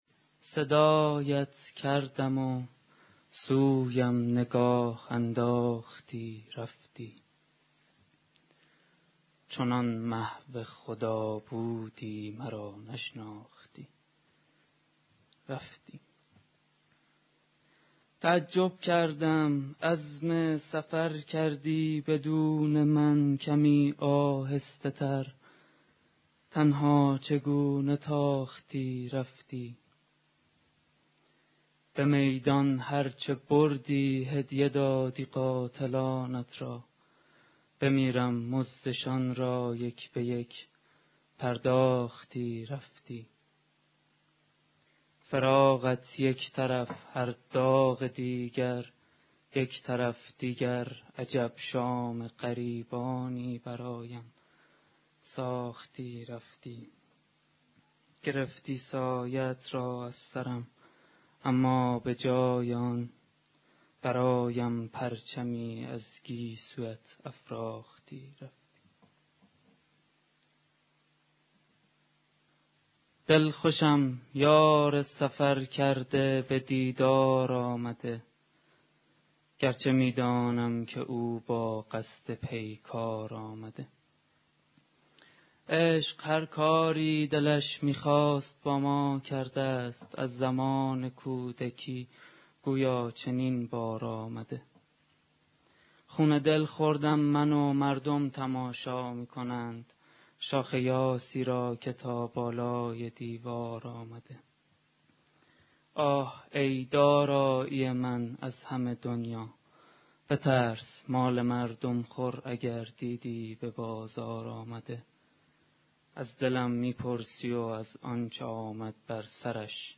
*** سیزدهمین محفل شعری گروه ادبی بداهه در حالی برگزار شد که شاعران حاضر در جلسه در بخش اول به شعر خوانی با موضوع " چهارپاره ها و ترانه های محرمی" و در بخش بعد به شعر خوانی با موضوع آزاد پرداختند.